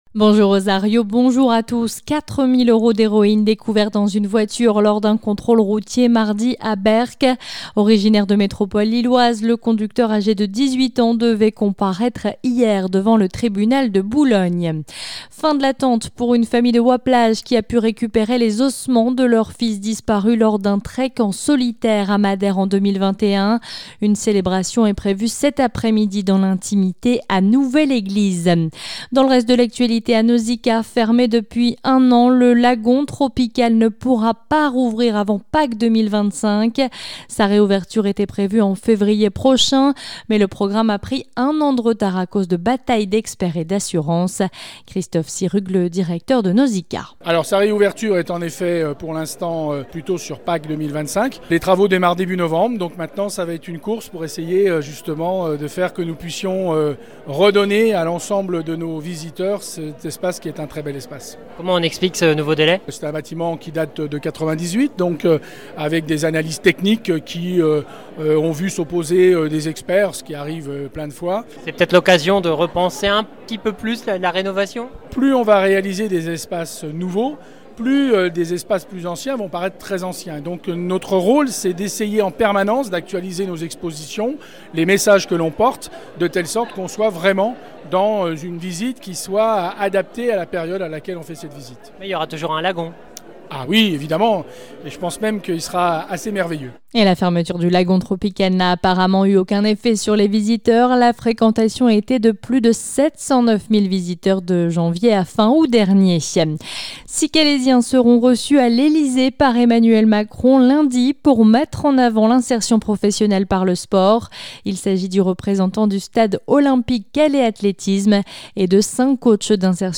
Le journal du samedi 21 octobre sur la Côte d'Opale et Picarde